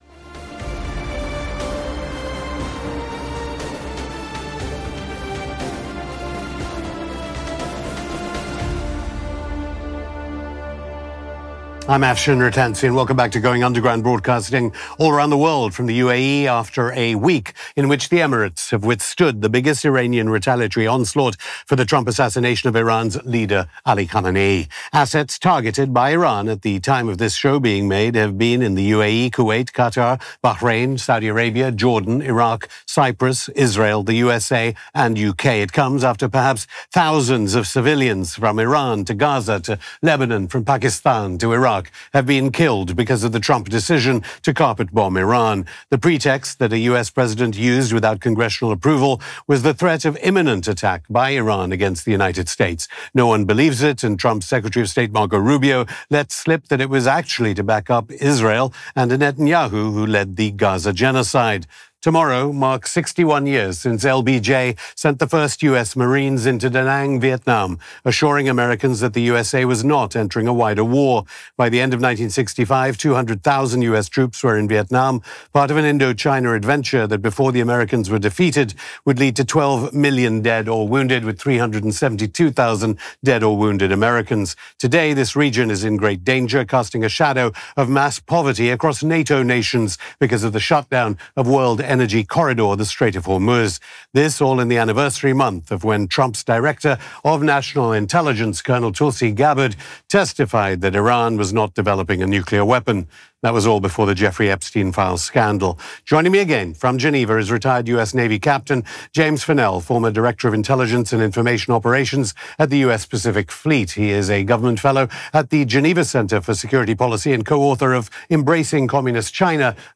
Going Underground Hosted by Afshin Rattansi